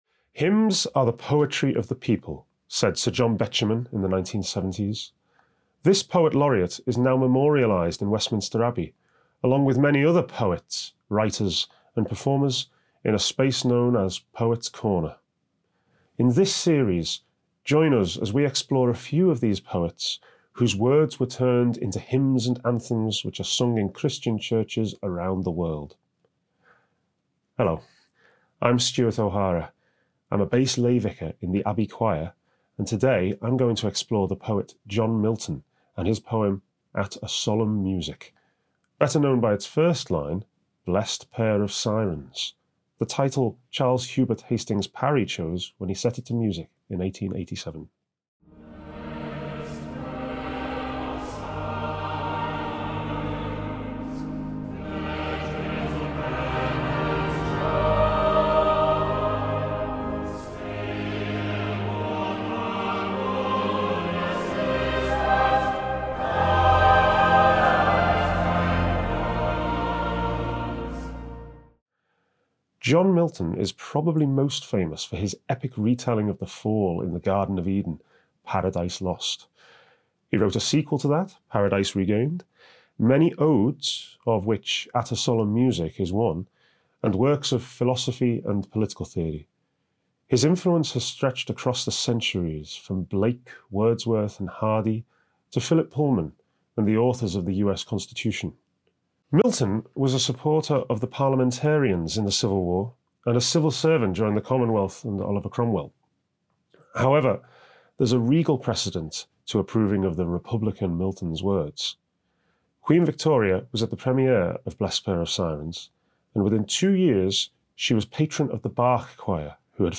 Hear staff from Westminster Abbey as they reflect on the context and the meaning of popular Christian hymns and anthems that came from poetry.